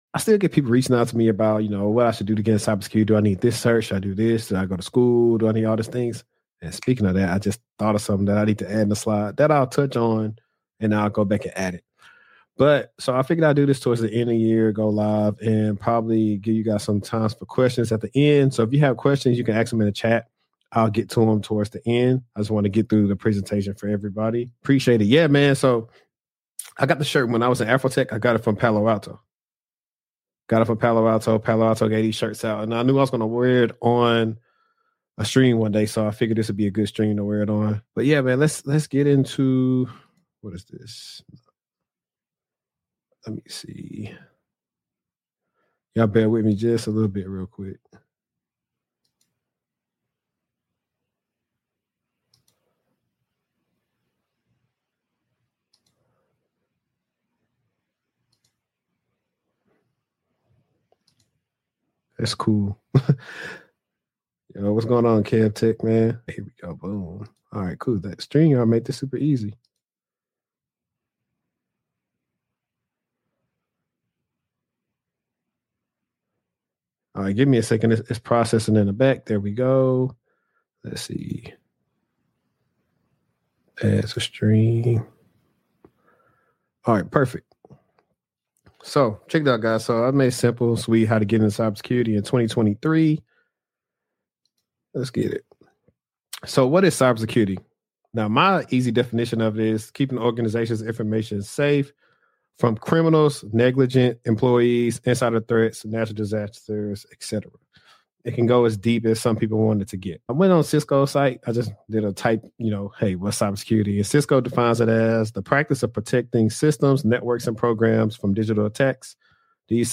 In this special livestream I show you how to get into cybersecurity without experience.